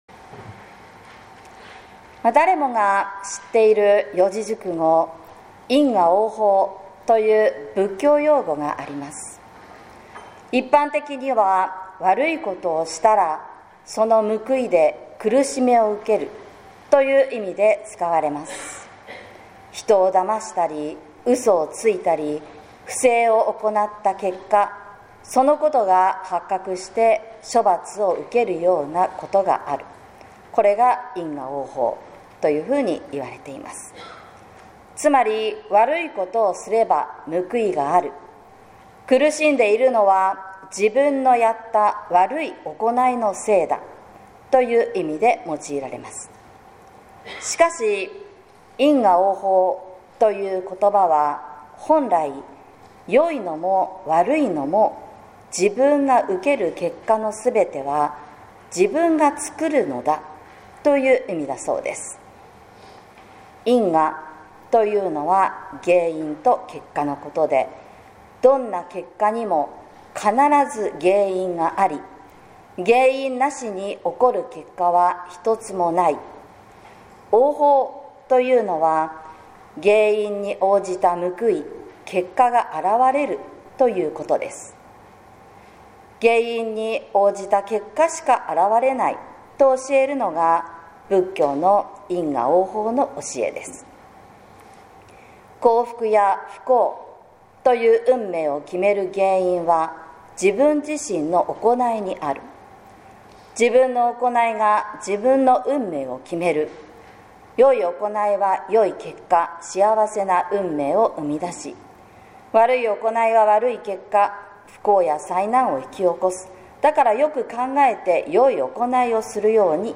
説教「喜び踊る日」（音声版）